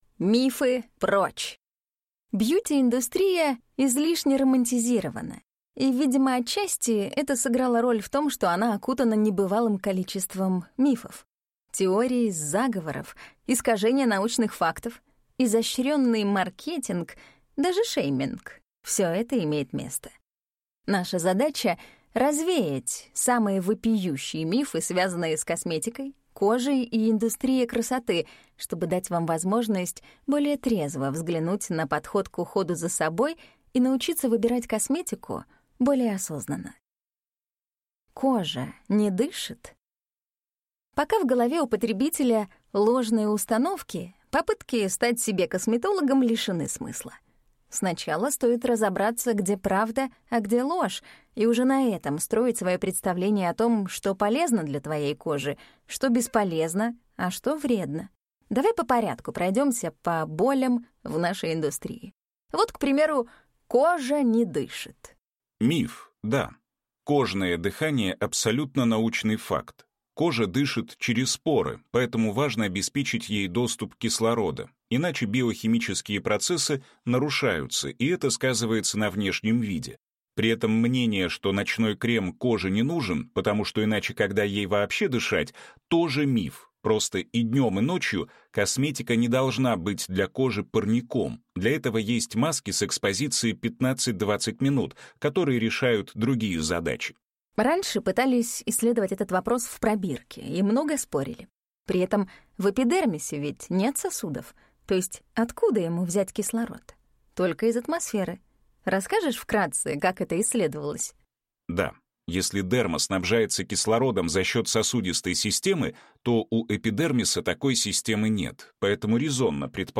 Аудиокнига Мифы, прочь!